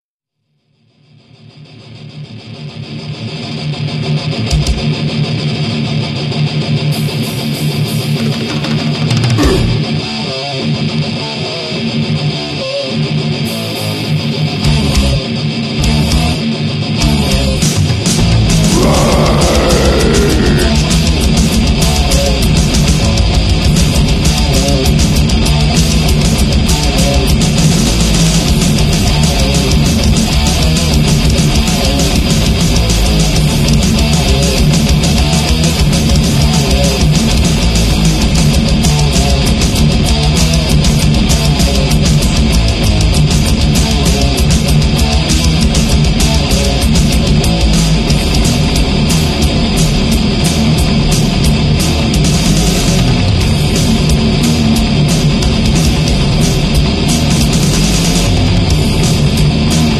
Genre:Death Metal